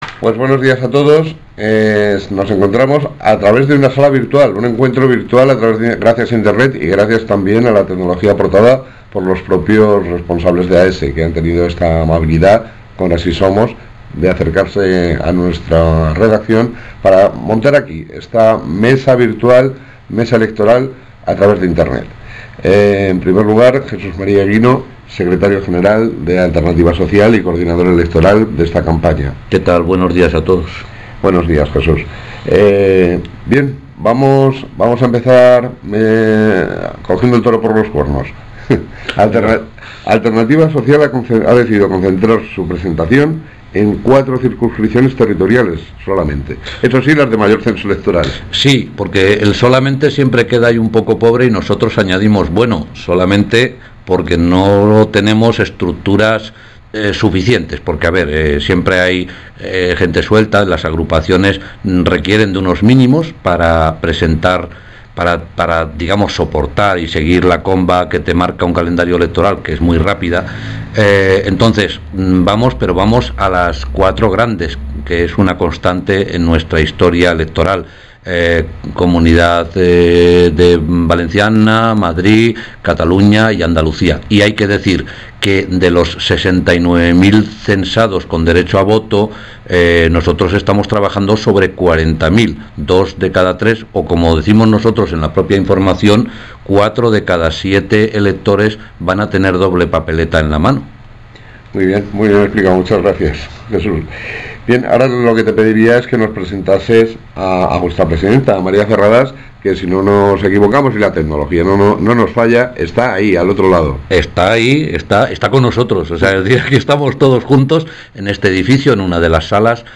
Entrevista con los cabezas de lista de Alternativa Social
Entrevista con los representantes de AS